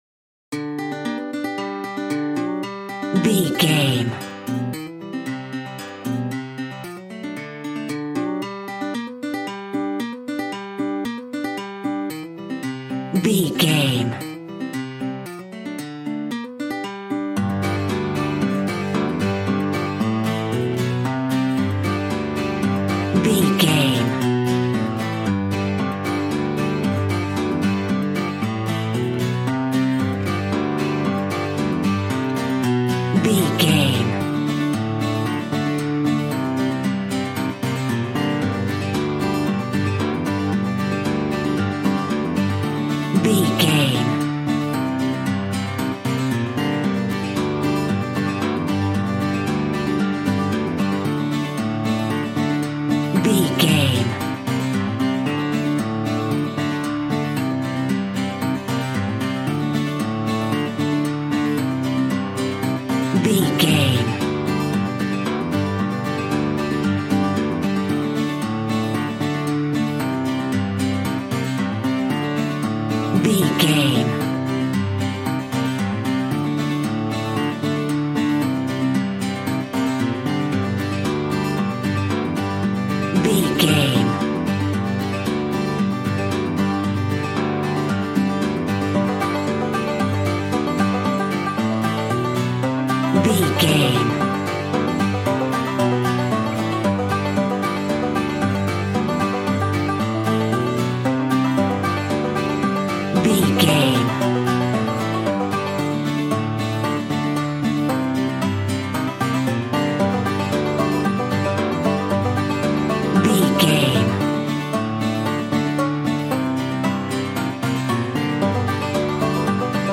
Bouncy and fun country fiddle music.
Ionian/Major
Fast
positive
double bass
drums
acoustic guitar